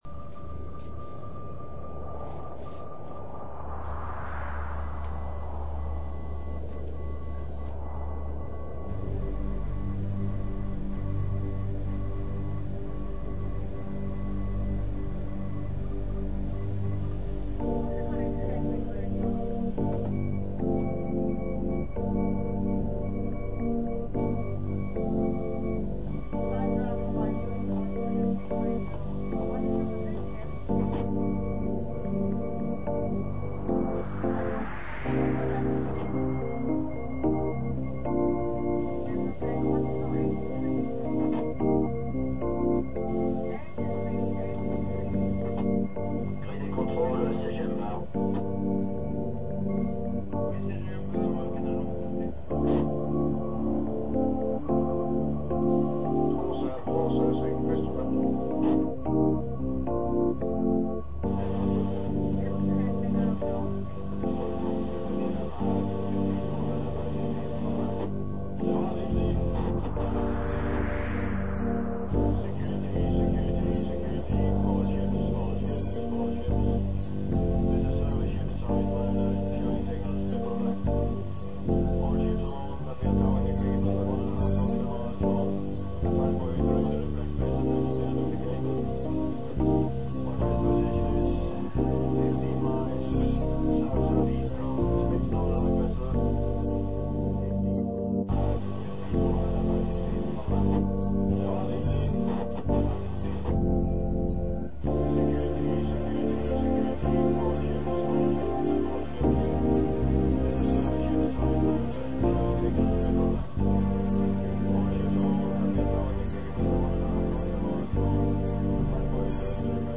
Erstmals habe ich bei dieser CD über eine MIDI-Schittstelle mit Cubase SX 3 auf einem Computer aufgenommen.